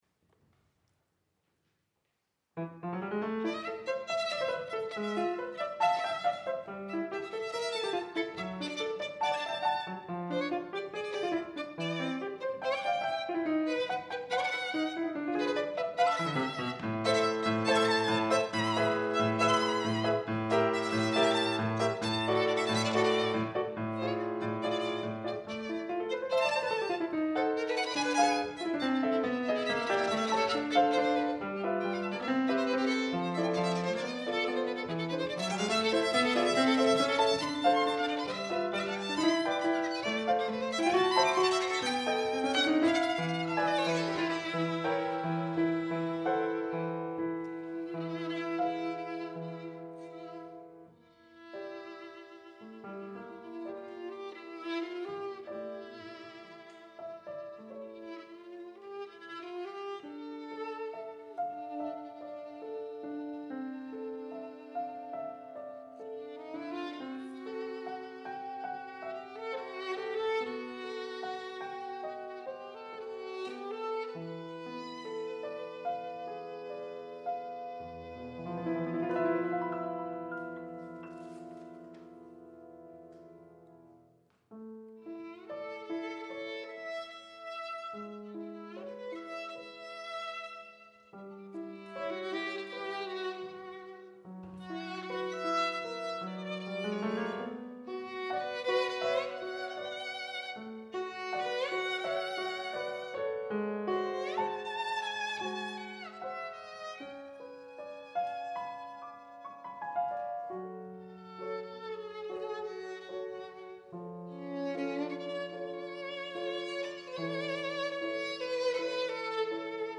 Concert Live recording